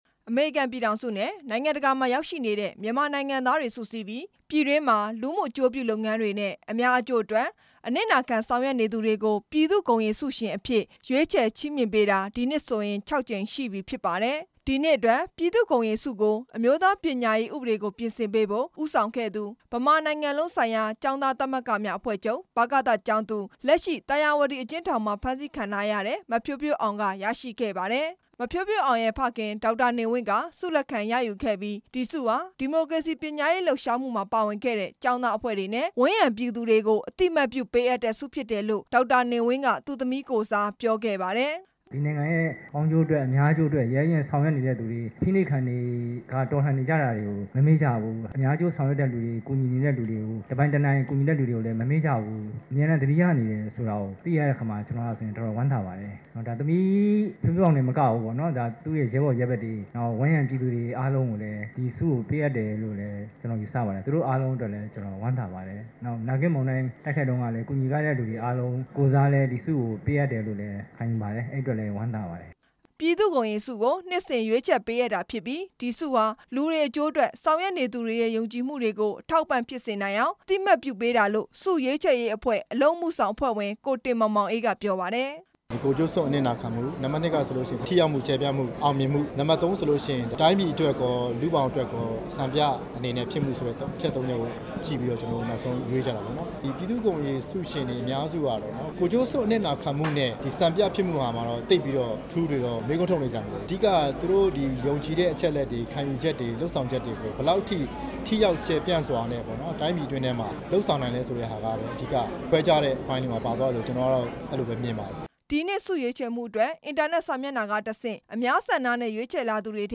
၂ဝ၁၅ ခုနှစ် ပြည်သူ့ဂုဏ်ရည်ဆုပေးပွဲအကြောင်း တင်ပြချက်
ဆုချီးမြှင့်တဲ့ အခမ်းအနားကို ရန်ကုန်မြို့ ဗဟန်းမြို့နယ်မှာရှိတဲ့ တော်ဝင်နှင်းဆီ စားသောက်ဆိုင်မှာ ကျင်းပခဲ့တာဖြစ်ပါတယ်။